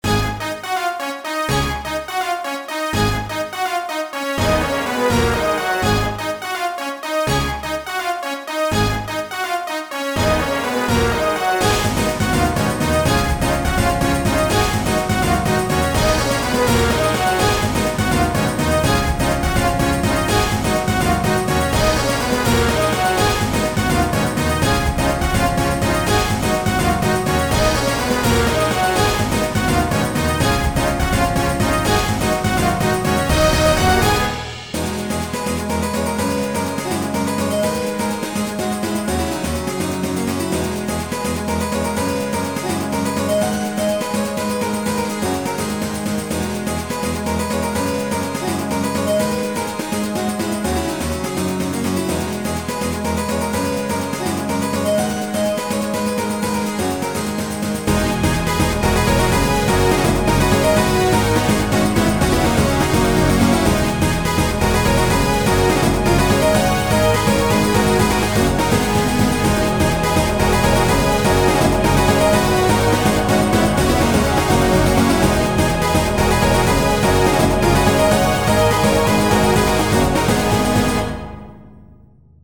game ost remastered soundtrack retro music
Ooooo very fun and energetic!